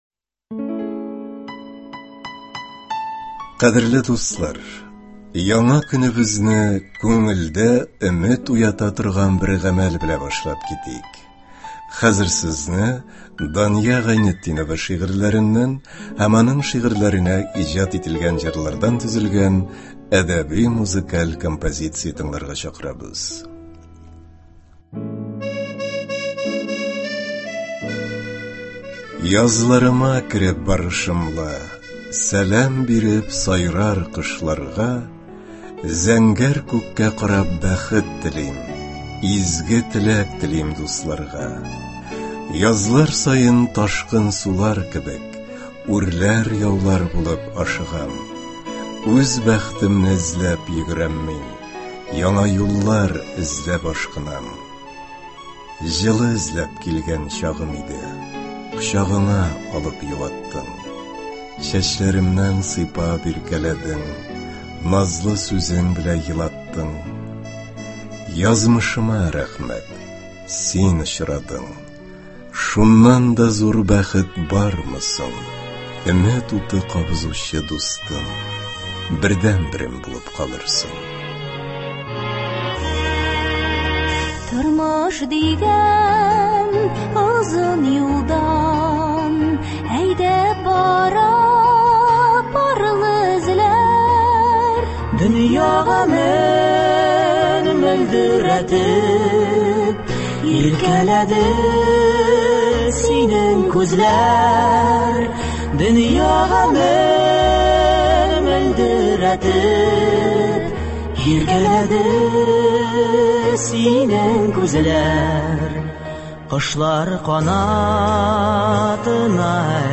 Әдәби-музыкаль композиция. 28 март.